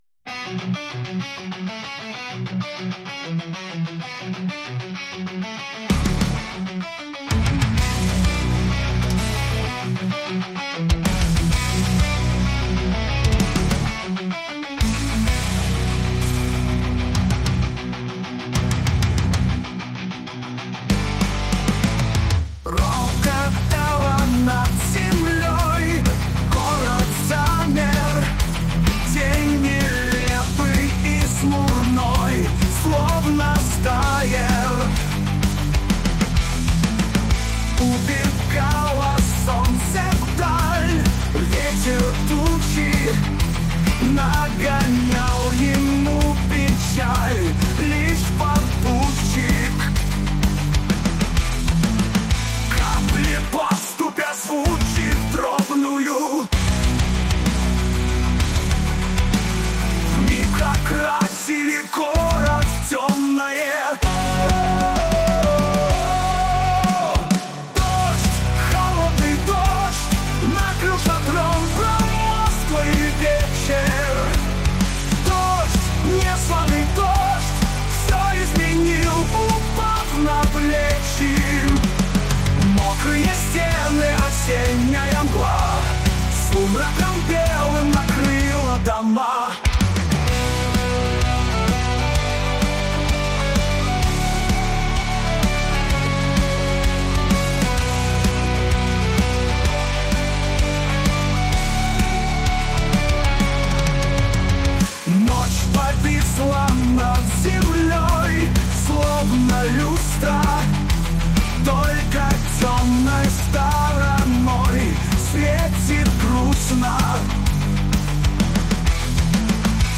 Нейросеть песню исполняет?